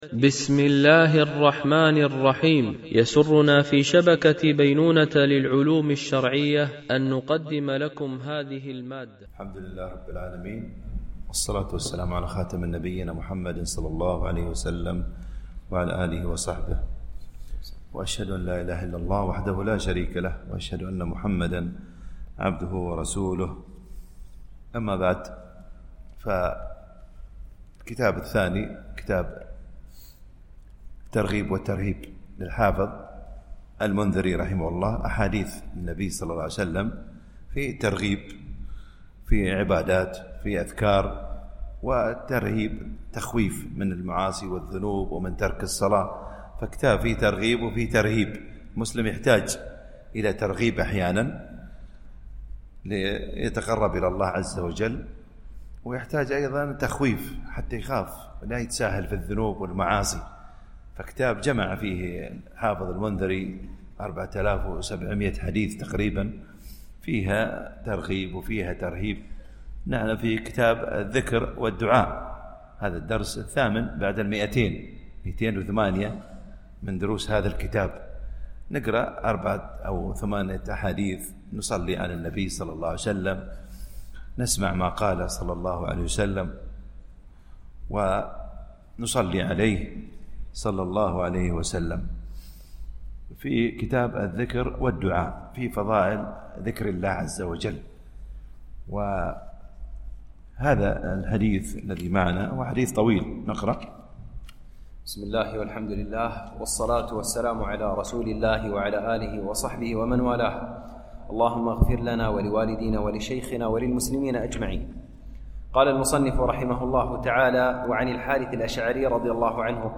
شرح كتاب الترغيب والترهيب - الدرس 208 ( كتاب الذكر والدعاء )